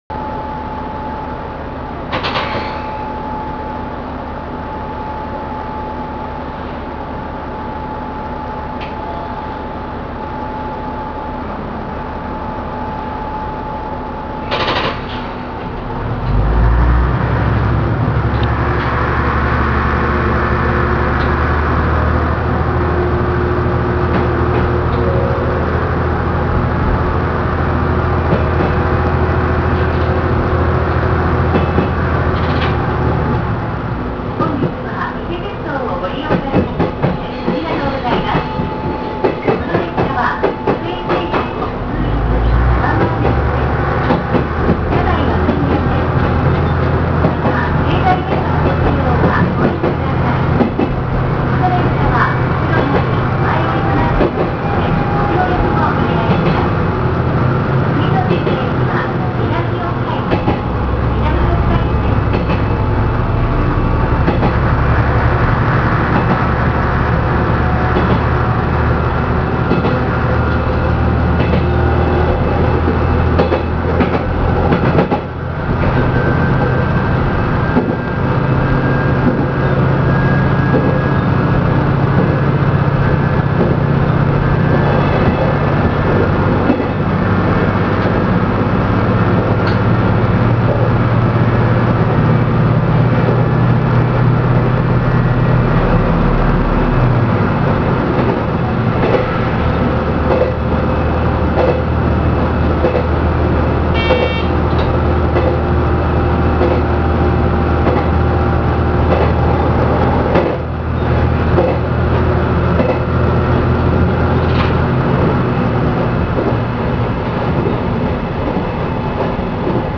・イセV形走行音
【関西本線】四日市〜南四日市（4分11秒：1.33MB）
軽快気動車だけあり、加速も減速もなかなかの速さ。
ドアチャイムがJR西のような4打点なのが大きな特徴です。